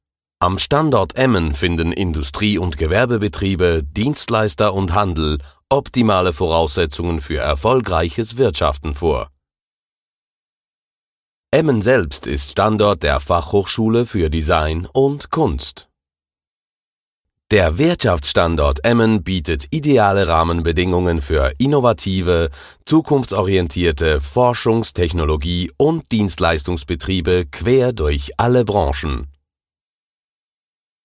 Sprechprobe: Industrie (Muttersprache):
Bilingual professional Voiceover Spanish (Castillian, Ibero-Spanish) & German. Deep, Believeable, smooth and engaging.